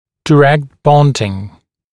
[dɪ’rekt ‘bɔndɪŋ] [daɪ-][ди’рэкт ‘бондин] [дай-]прямая фиксация, прямое приклеивание